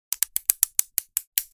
Rotating Sprinkler Mechanical, Garden, Outdoor Sound Effect Download | Gfx Sounds
Rotating-sprinkler-mechanical-garden-outdoor.mp3